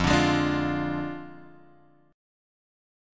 F Minor 6th
Fm6 chord {1 x 0 1 3 1} chord